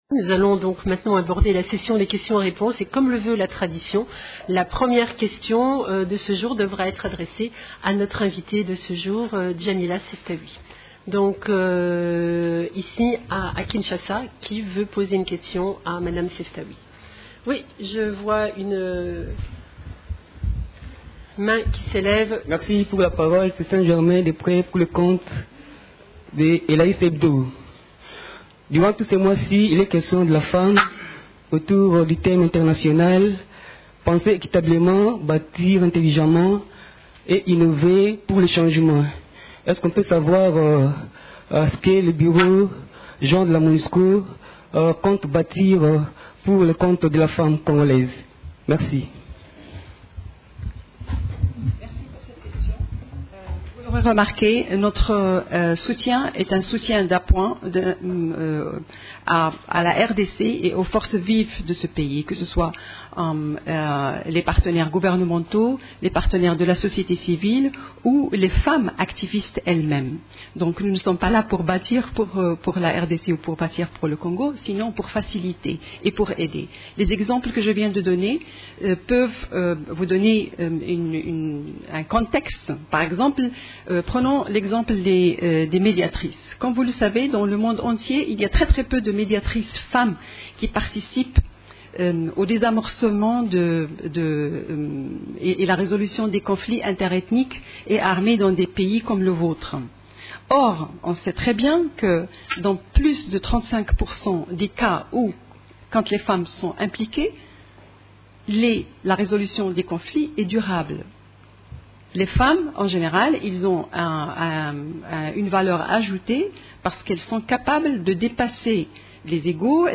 Conférence de presse du mercredi 13 mars 2019